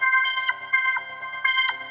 SaS_MovingPad04_125-C.wav